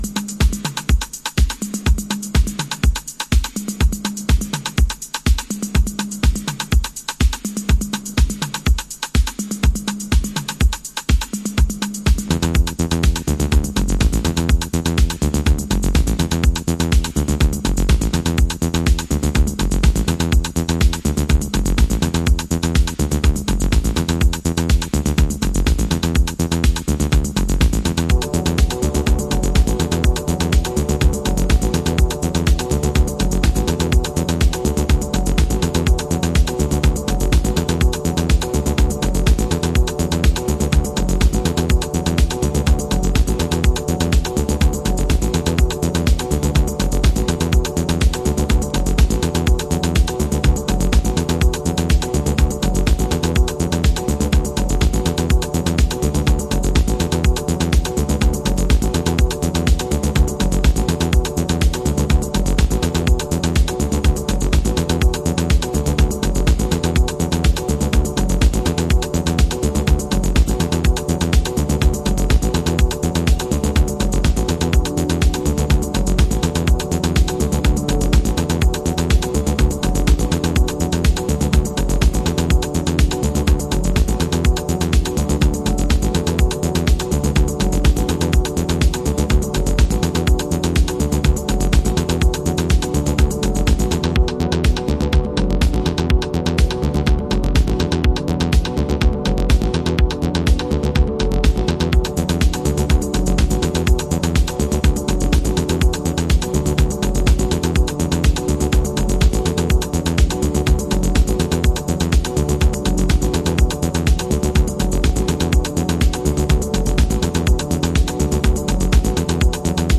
Chicago Oldschool / CDH
Instrumental Original